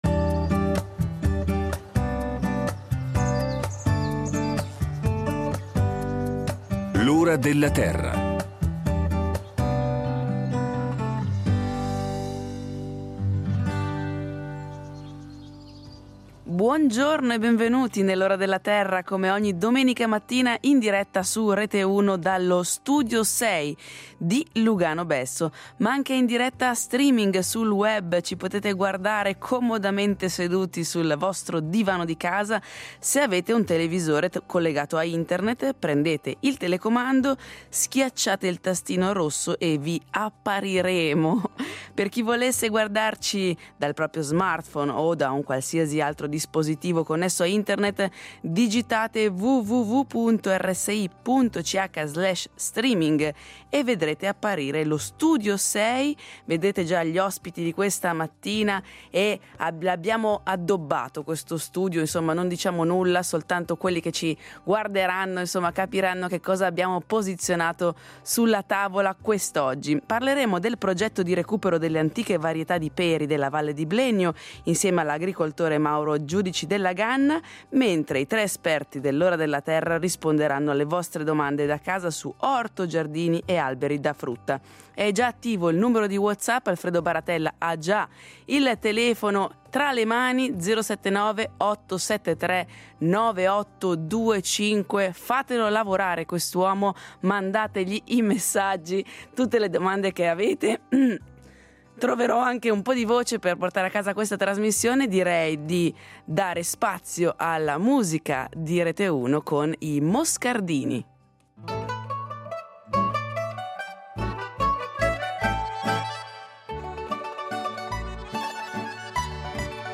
Le linee telefoniche saranno aperte per porre domande all’ospite presente in studio e agli esperti